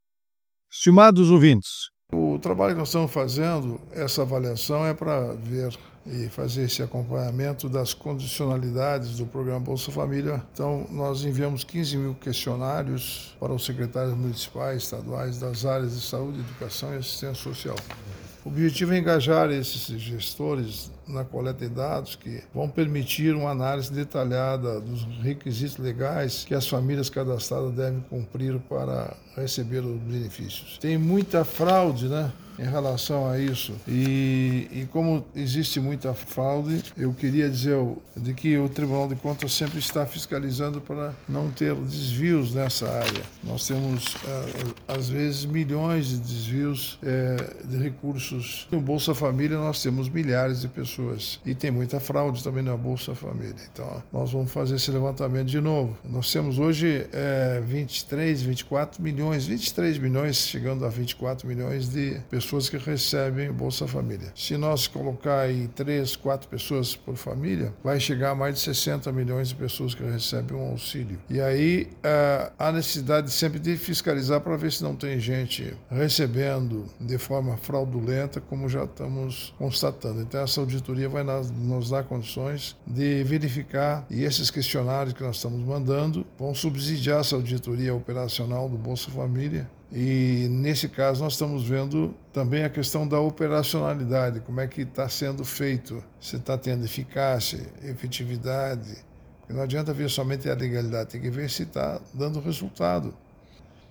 Comentário de Augusto Nardes, ministro do TCU.